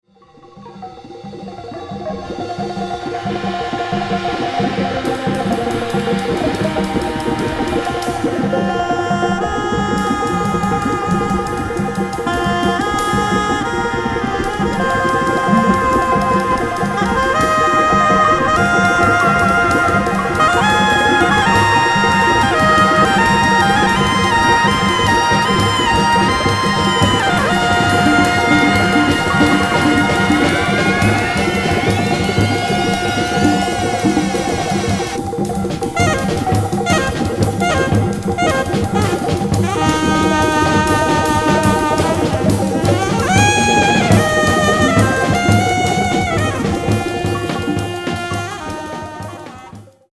即興　アフロ　電子音